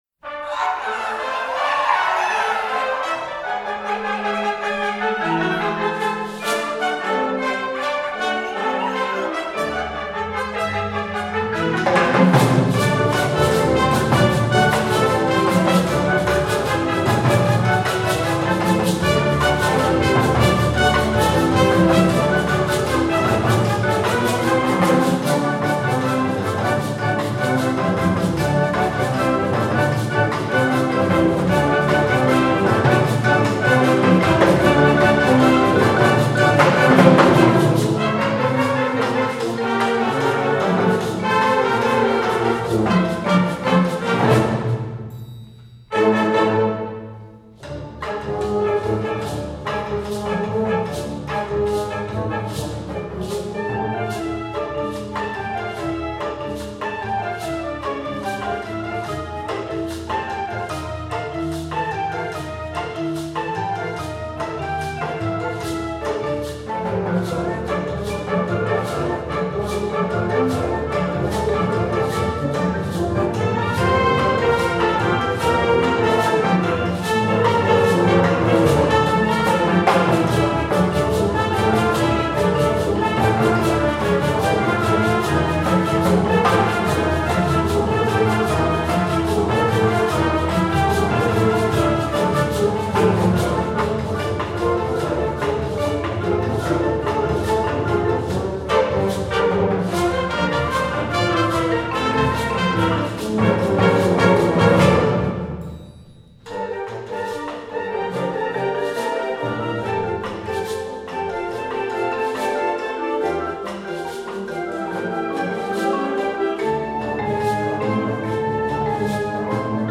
Gusto El Sabor–for Symphonic Concert Band.  This piece was premiered at Carnegie Hall.